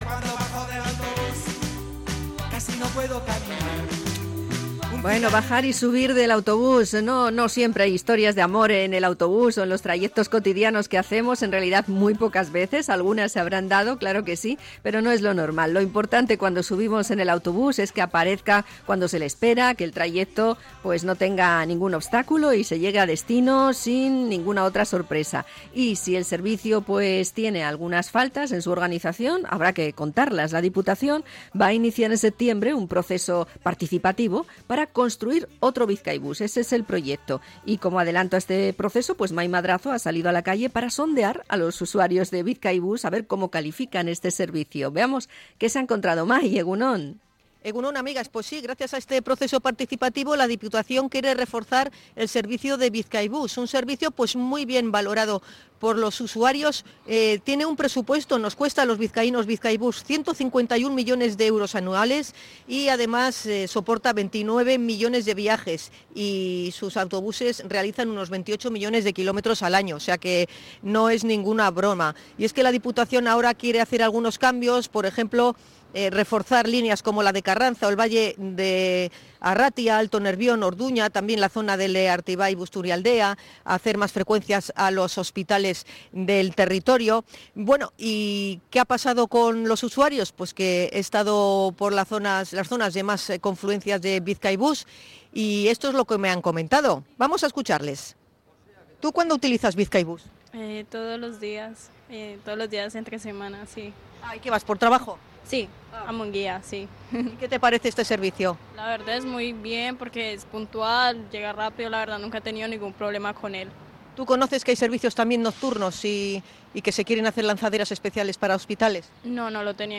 Encuesta callejera sobre las impresiones sobre el servicio